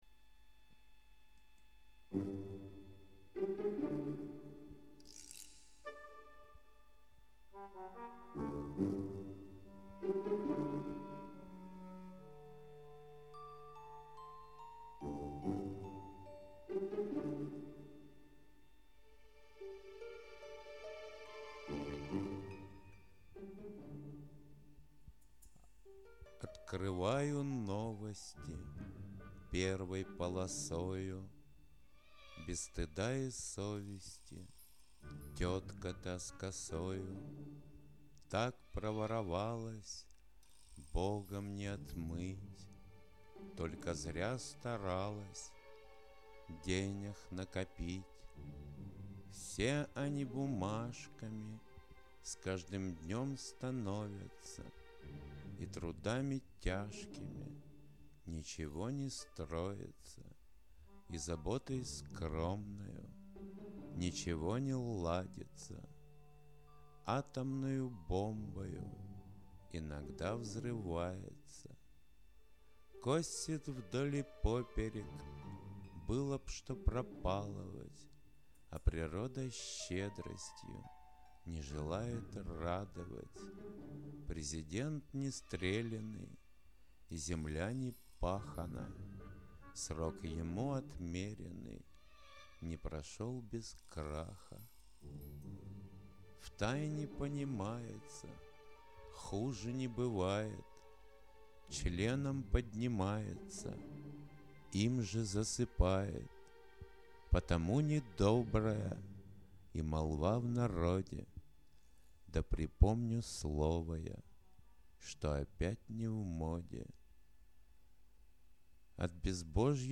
Авторское исполнение-